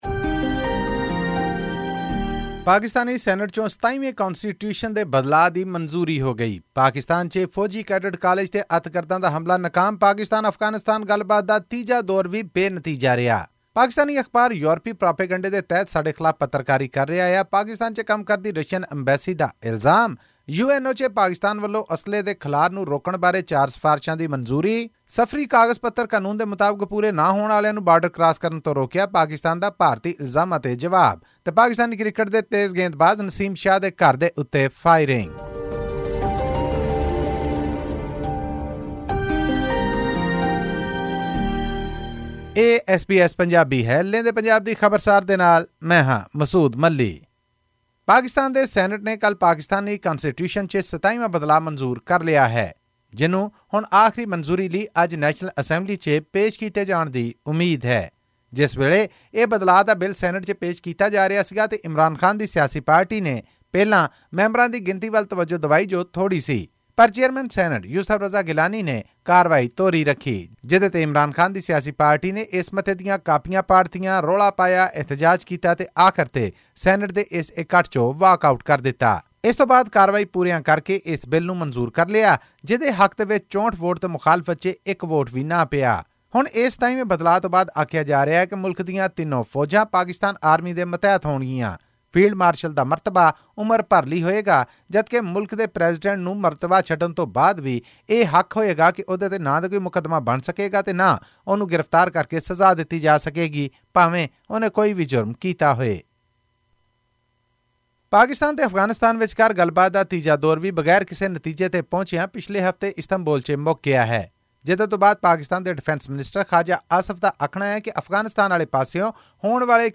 ਪਾਕਿਸਤਾਨ ਸਰਕਾਰ ਦਾ ਕਹਿਣਾ ਹੈ ਕਿ ਇਹ ਸਾਰੀ ਕਾਰਵਾਈ ਕਾਨੂੰਨਾਂ ਮੁਤਾਬਿਕ ਕੀਤੀ ਗਈ ਸੀ ਅਤੇ ਇਸ ਨੂੰ ਧਾਰਮਿਕ ਰੰਗਤ ਦੇਣਾ ਸਹੀ ਨਹੀਂ ਹੈ। ਹੋਰ ਵੇਰਵੇ ਲਈ ਸੁਣੋ ਇਹ ਰਿਪੋਰਟ...